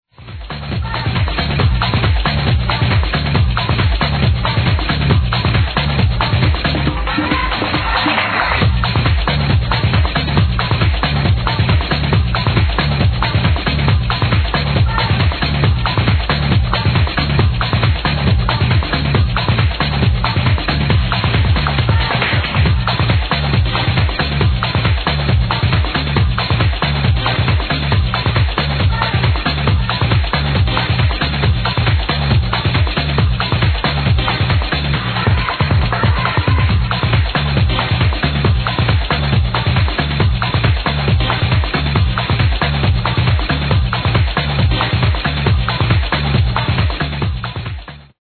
groovy techno track id... a real floorkiller!!!
please help me id this latin influenced techno track!